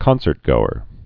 (kŏnsərt-gōər)